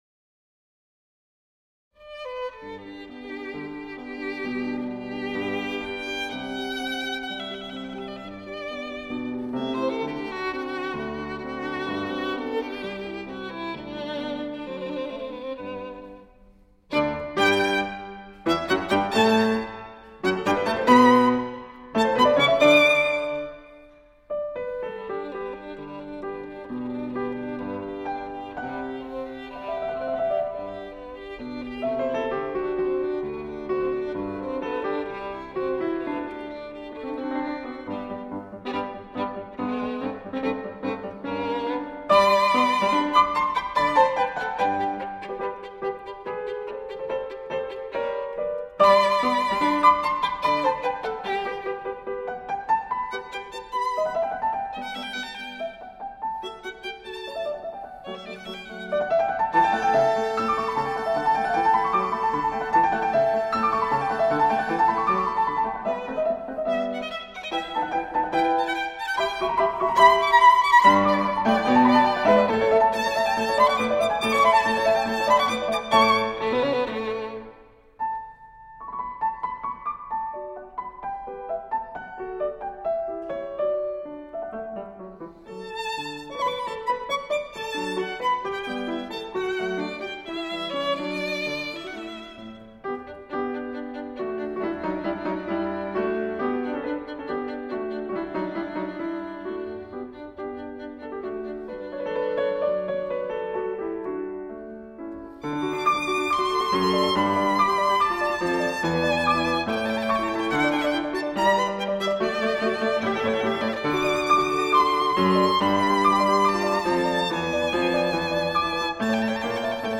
钢琴